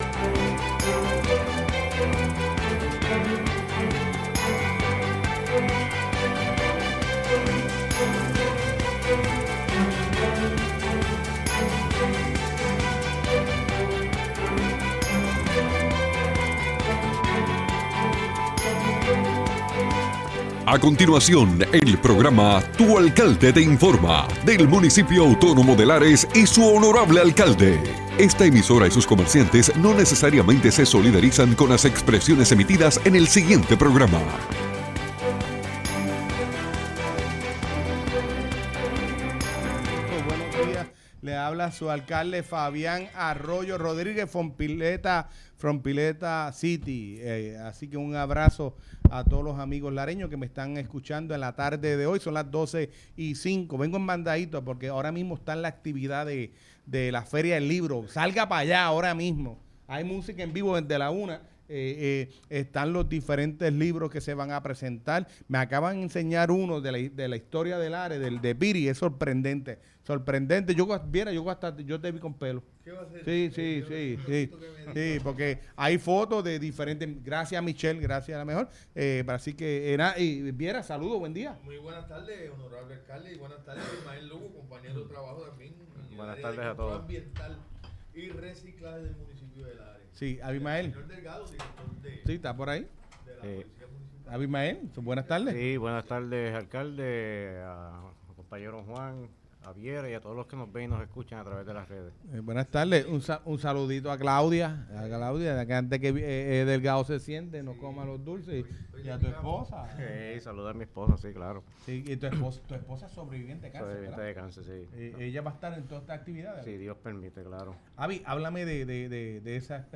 El honorable alcalde de Lares, Fabián Arroyo, junto a su equipo de trabajo nos informan sobre las novedades del municipio.